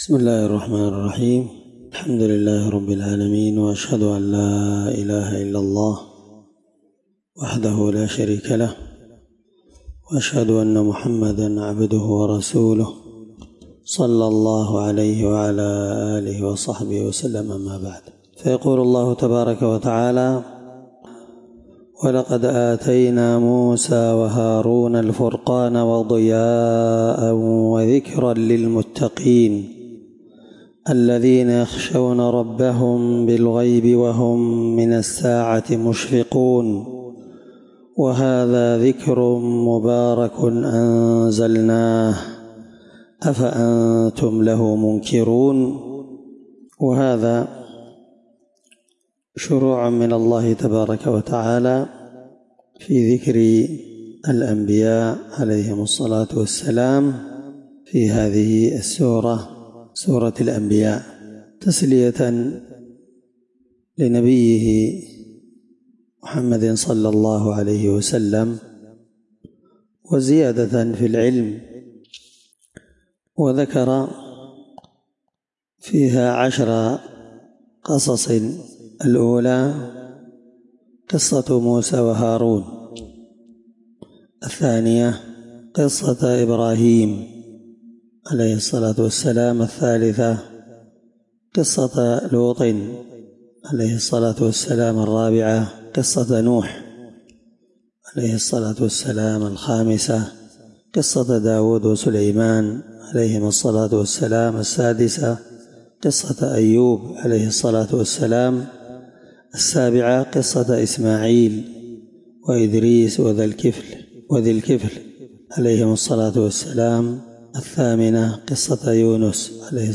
الدرس17تفسير آية (48-50) من سورة الأنبياء
21سورة الأنبياء مع قراءة لتفسير السعدي